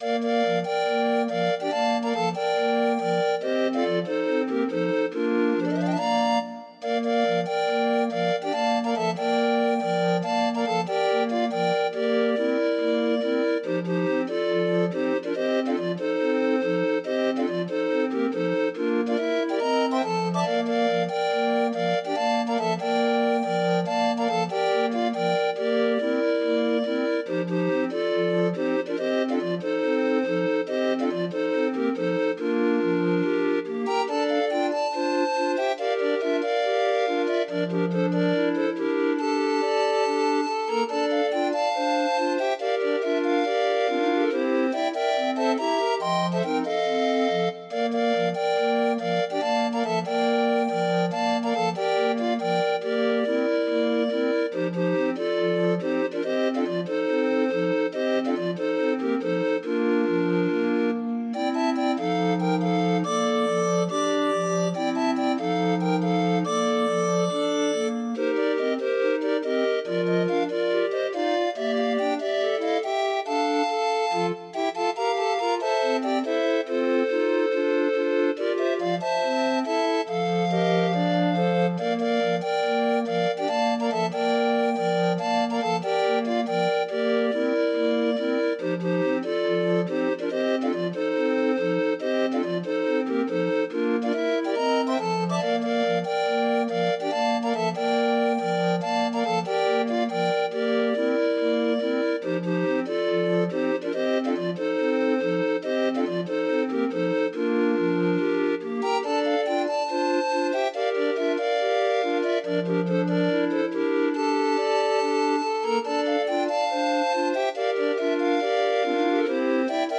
Demo of 20 note MIDI file.